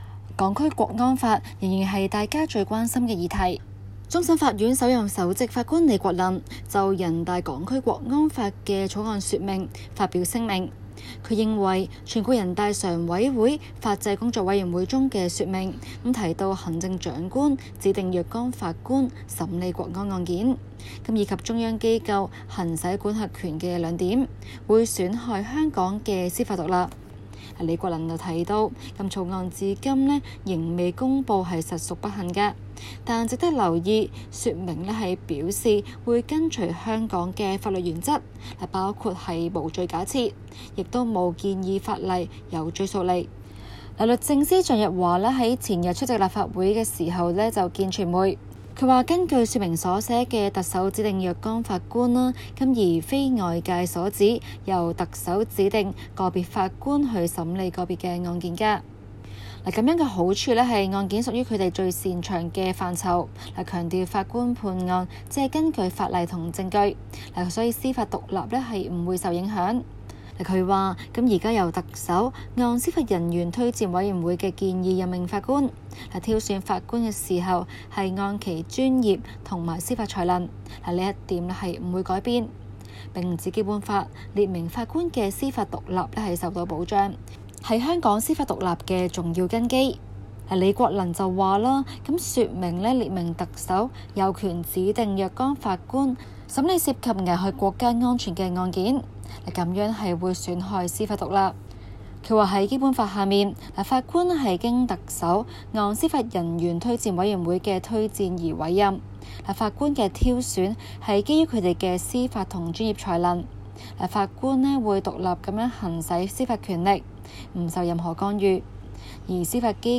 今期 【中港快訊 】環節報導各界繼續就人大在香港推出港區國安法一事，提出不同意見。